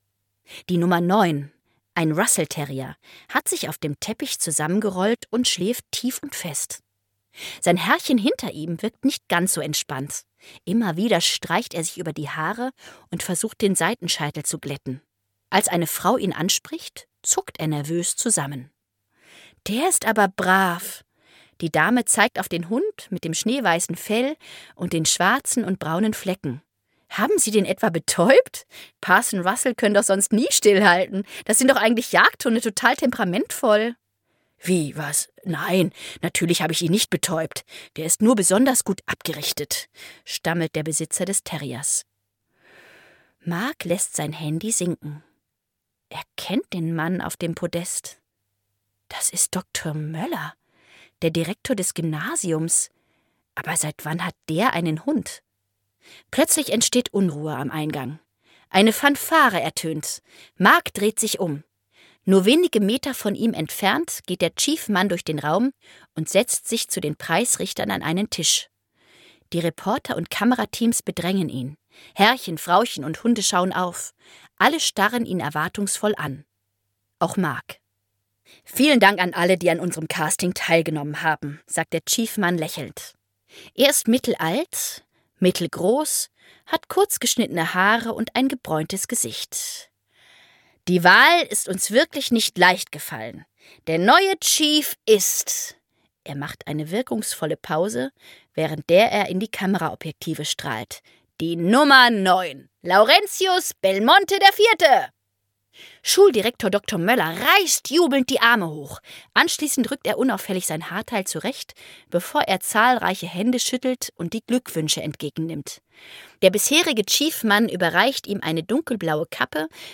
Conni & Co: Conni & Co - Das Hörbuch zum Film - Dagmar Hoßfeld - Hörbuch